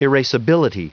Prononciation du mot erasability en anglais (fichier audio)
Prononciation du mot : erasability